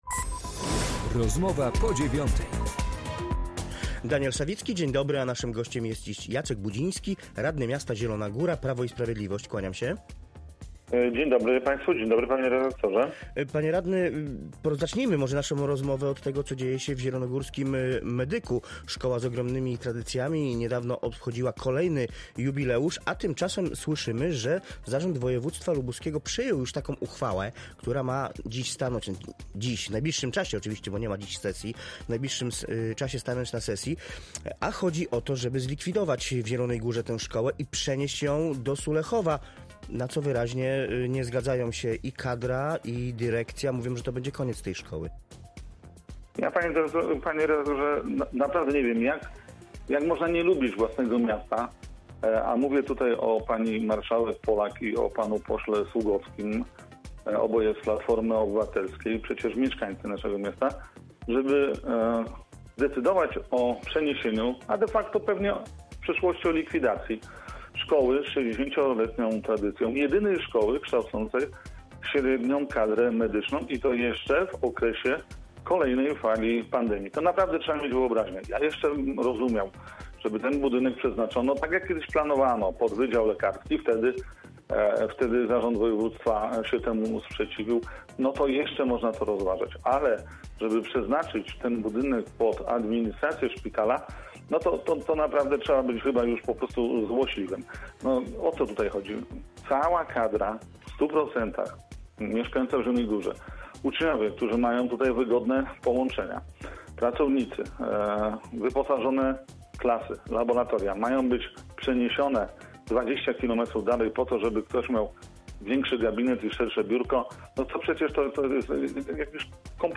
Z radnym Jackiem Budzińskim rozmawia